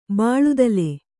♪ bāḷudale